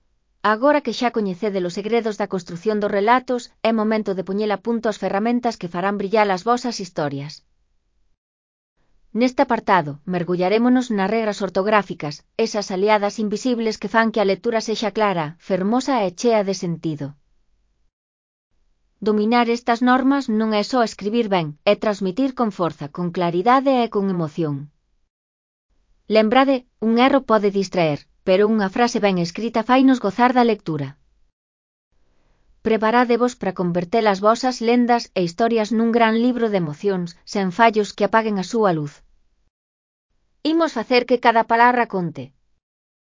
Elaboración propia (proxecto cREAgal) con apoio de IA voz sintética xerada co modelo Celtia. Orientacións 3.3 (CC BY-NC-SA)